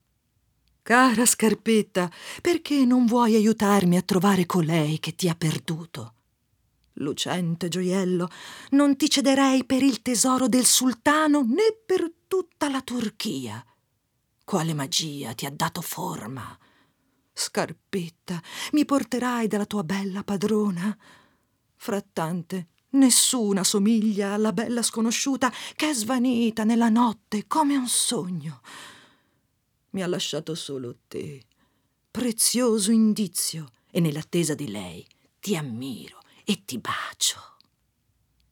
FIABA
Si possono ascoltare la voce narrante, rumori e musiche.